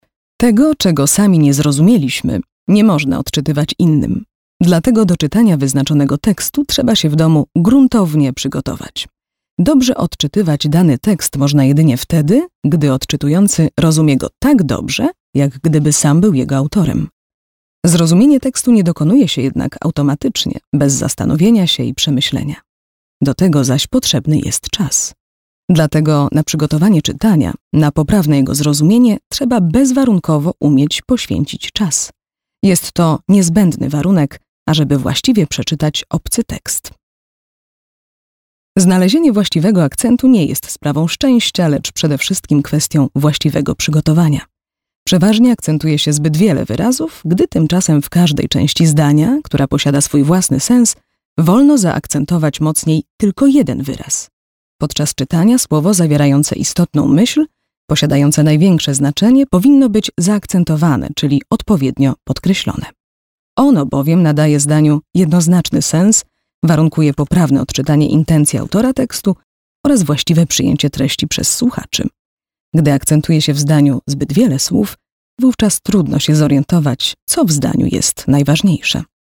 Znani i lubiani Kobieta 30-50 lat
Dziennikarka radiowa i telewizyjna, lektorka, konferansjerka, trenerka mówców i specjalistka ds. kształcenia głosu i mowy
Nagranie lektorskie